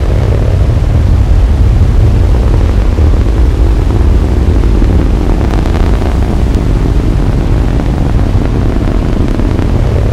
ssc_thruster3w.wav